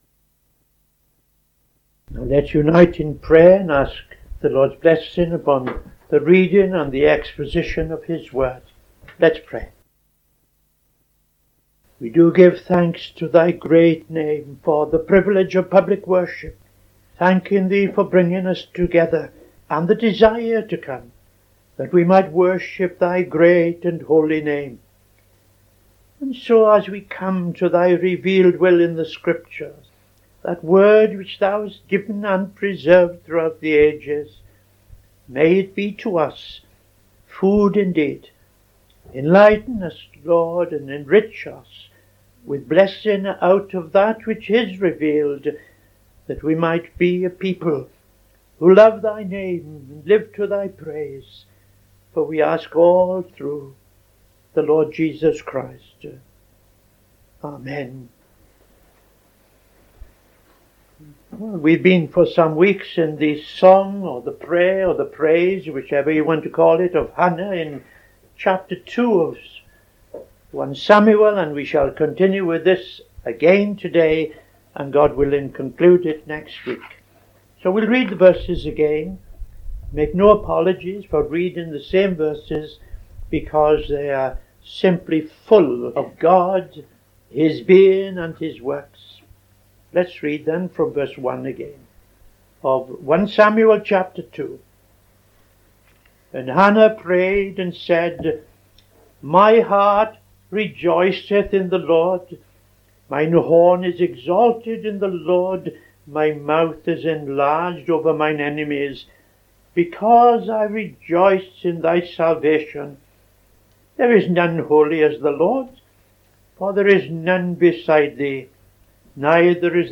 Bible Study - TFCChurch